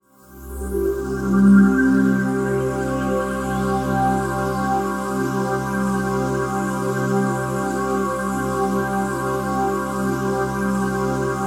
ATMOPAD08 -LR.wav